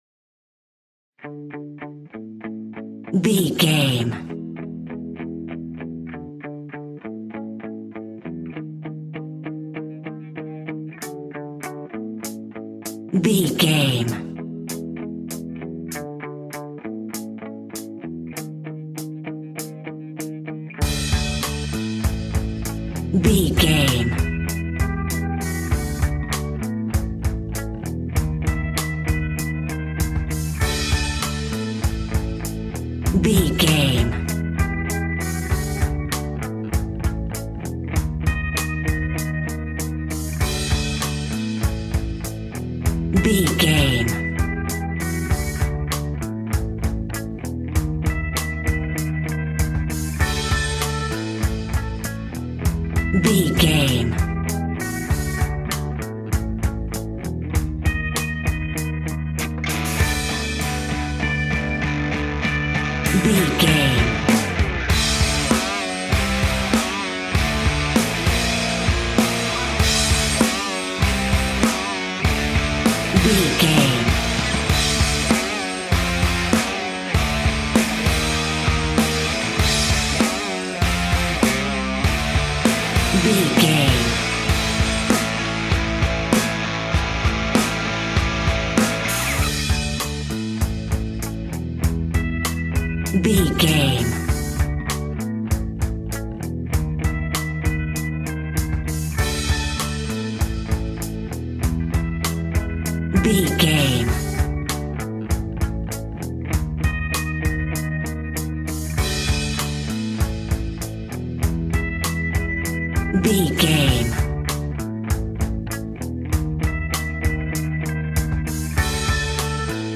Epic / Action
Fast paced
Mixolydian
hard rock
heavy metal
dirty rock
rock instrumentals
Heavy Metal Guitars
Metal Drums
Heavy Bass Guitars